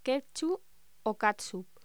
Locución: Ketchup o catsup
voz